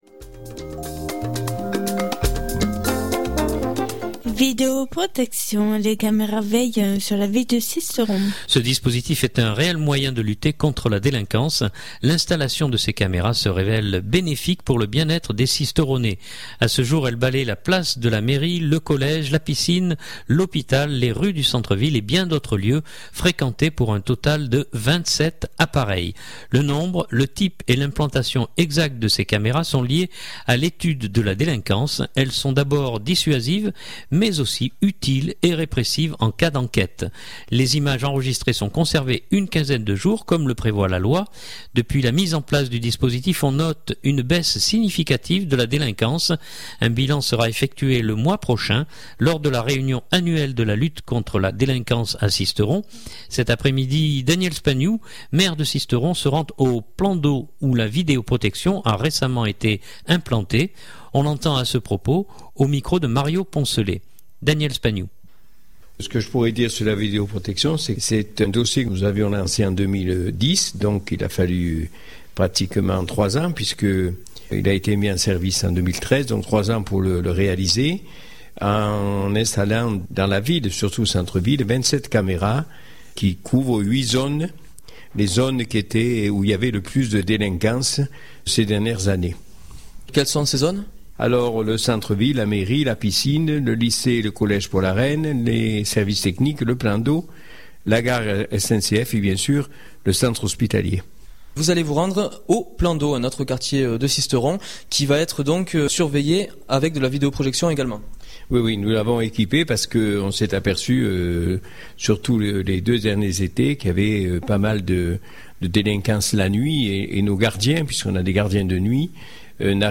Cet après-midi, Daniel Spagnou, Maire de Sisteron, se rend au plan d’eau où la vidéo protection a récemment été implantée.